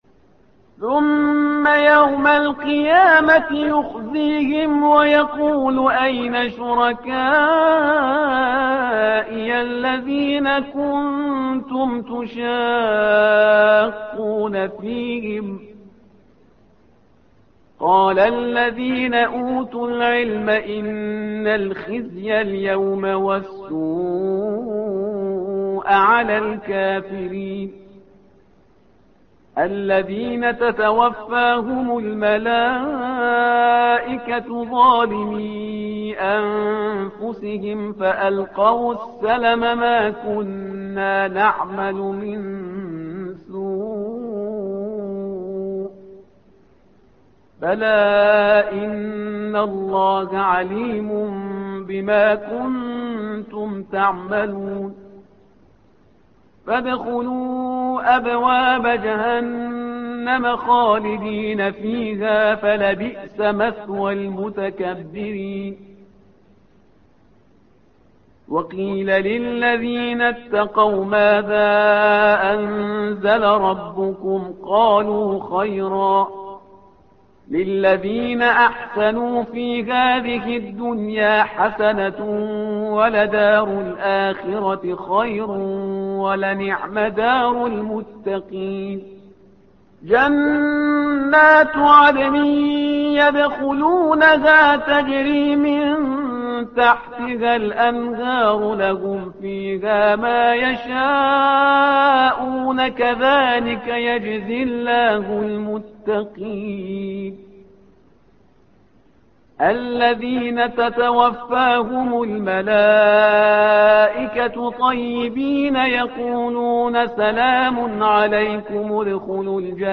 تحميل : الصفحة رقم 270 / القارئ شهريار برهيزكار / القرآن الكريم / موقع يا حسين